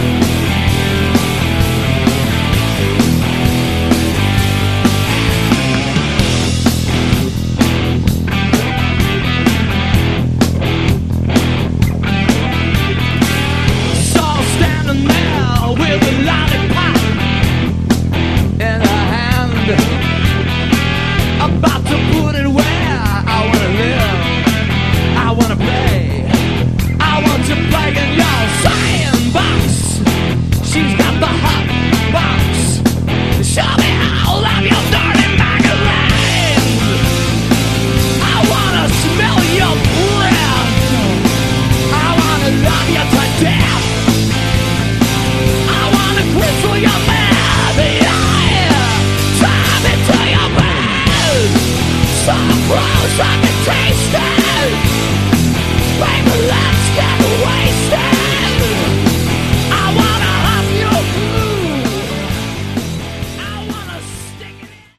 Category: Glam
guitar
vocals
bass
drums